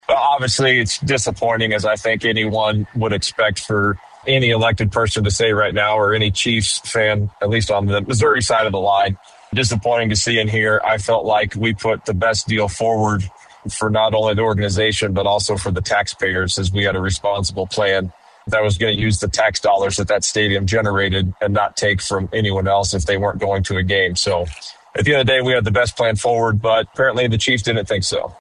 Gregory spoke to KMMO News shortly after the announcement that the Chiefs would leave Arrowhead for a new stadium in Kansas beginning in 2031.